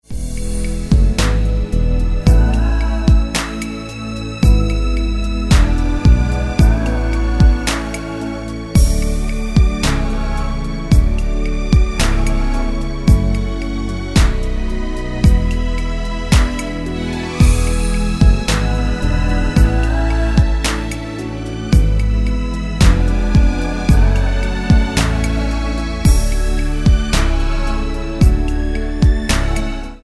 Tonart:A-C-Ab-Db mit Chor